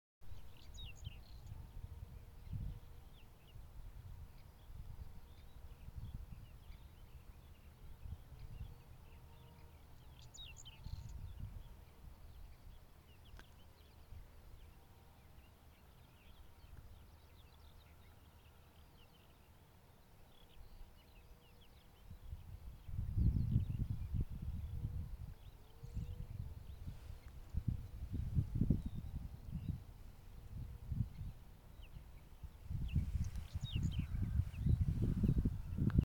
луговой чекан, Saxicola rubetra
СтатусПоёт